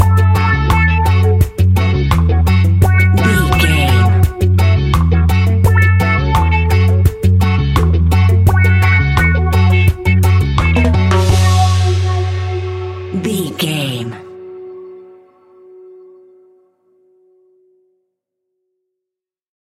Classic reggae music with that skank bounce reggae feeling.
Aeolian/Minor
F#
laid back
chilled
off beat
drums
skank guitar
hammond organ
percussion
horns